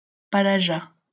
Palaja (French pronunciation: [palaʒa]